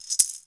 SHAKER DS1.wav